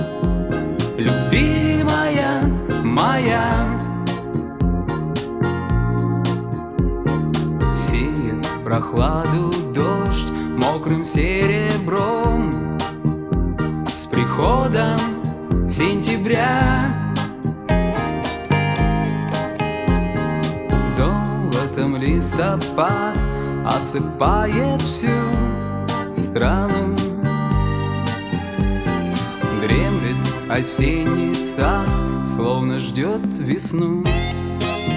Жанр: Русский поп / Русский рэп / Русский шансон / Русские